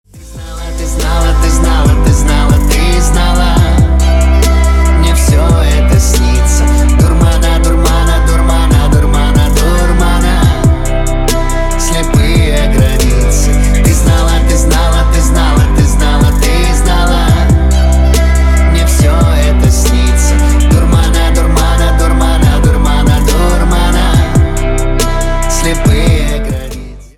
• Качество: 320, Stereo
громкие
красивый мужской голос
alternative